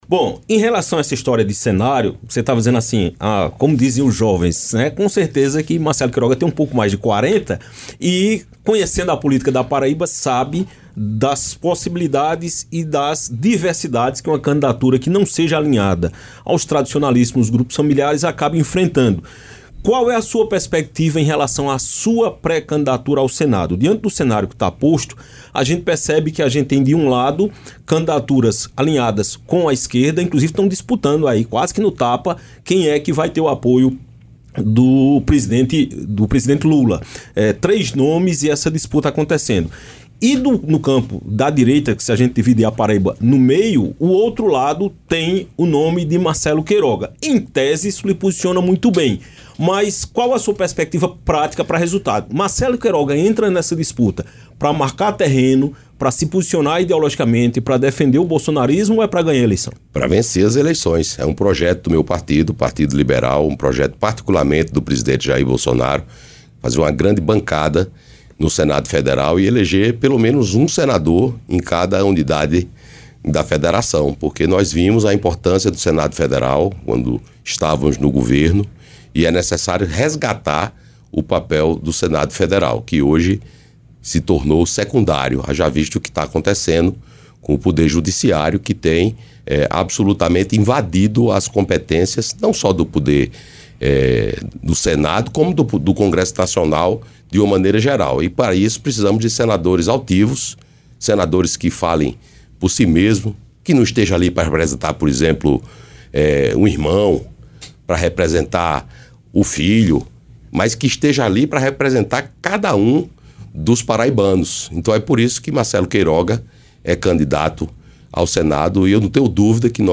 O presidente do Partido Liberal (PL) na Paraíba e pré-candidato ao Senado, Marcelo Queiroga, concedeu entrevista nesta quarta-feira (28) ao Jornal do Meio Dia, da Rádio Campina FM.